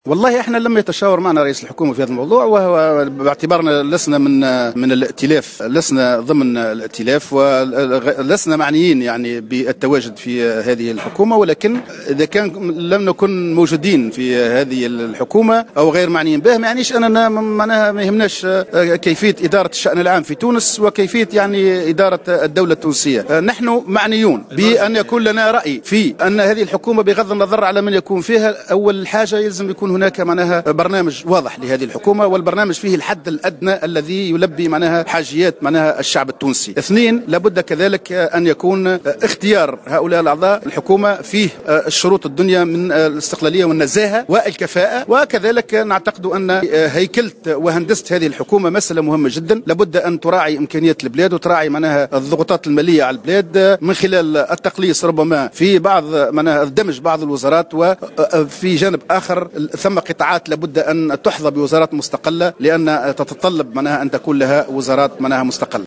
علق الأمين العام لحزب التيار الشعبي زهير حمدي خلال اجتماع مجلسه الوطني أمس السبت 26 ديسمبر 2015 بسوسة على التحوير الوزاري المرتقب.